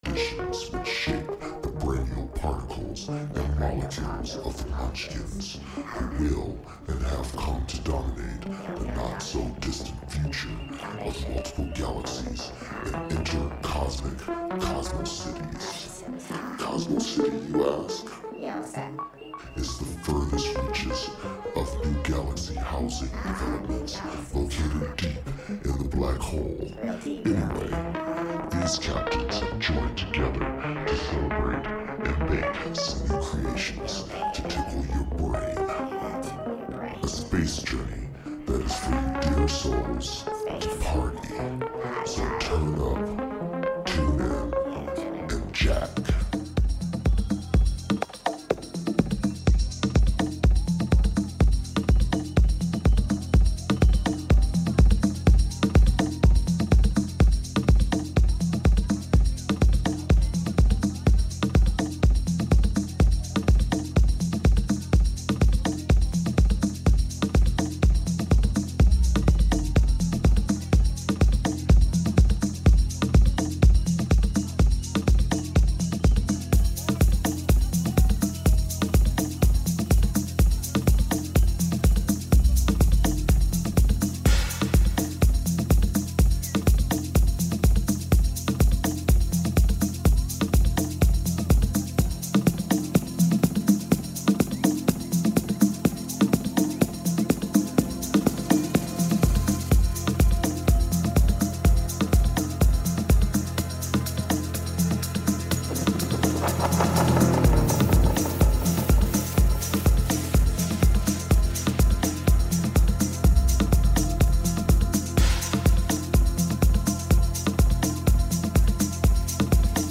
Also find other EDM Livesets, DJ Mixes and
Liveset/DJ mix